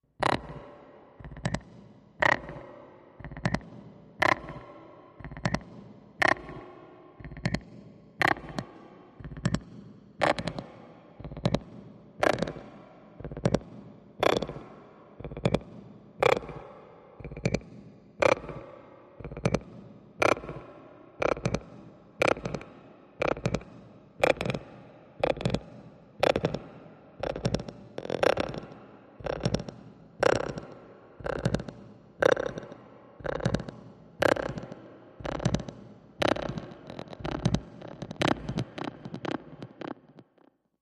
Junior Robot, Machine, Robot Voice, Breathy, Pulsing